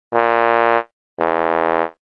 Efectos de sonido
ERROR FALLO SONIDO GRATIS DESCARGA DIRECTA
error-fallo-sonido_gratis_descarga_directa.mp3